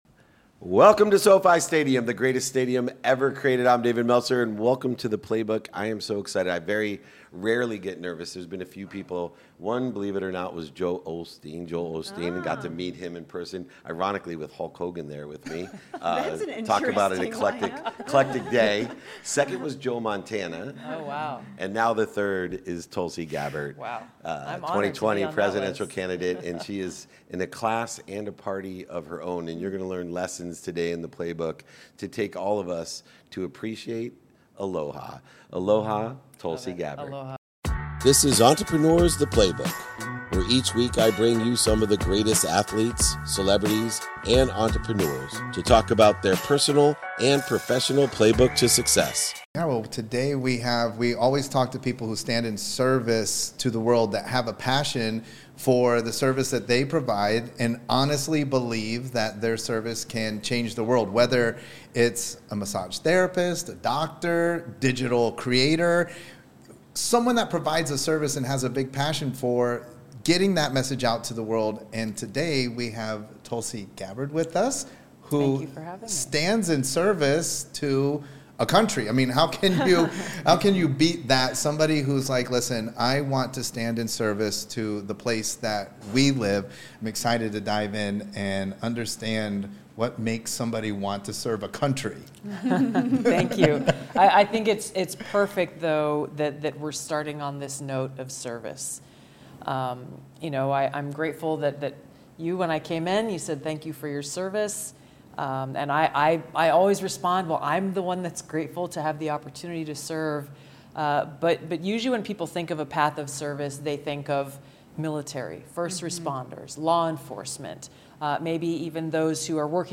In today’s episode, I'm joined by Tulsi Gabbard, a trailblazer in American politics and a United States Army Reserve officer.